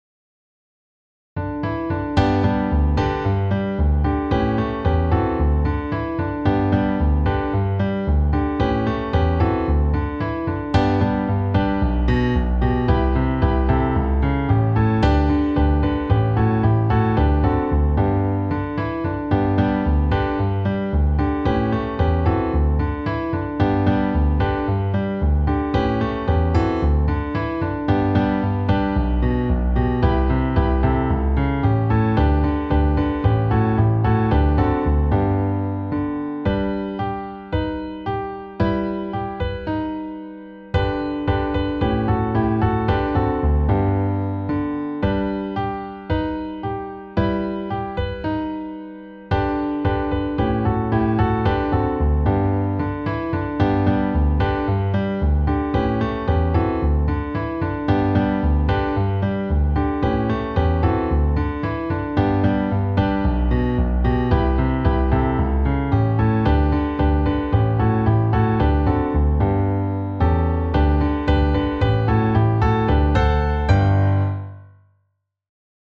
Instrumentierung: Klavier solo